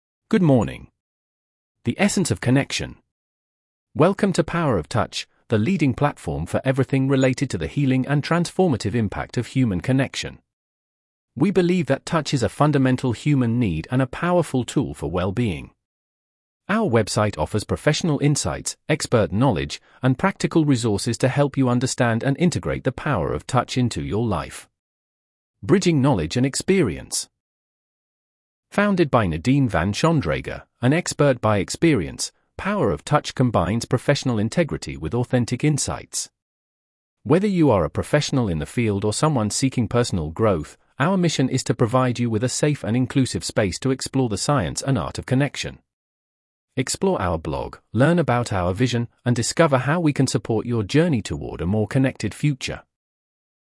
mp3-text-to-voice-homepage-good-morning-power-of-touch.mp3